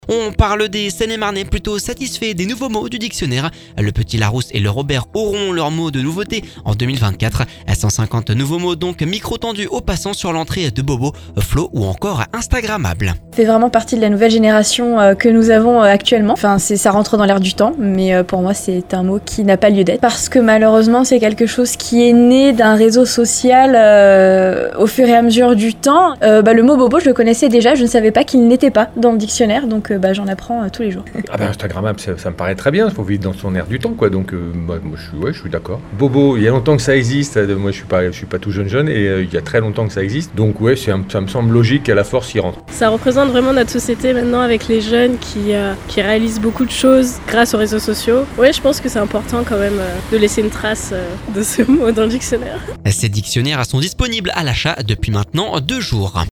Ces dictionnaires sont disponibles à l'achat depuis deux jours Micro tendu aux passants sur l'entrée de bobo, flow ou encore instagrammable.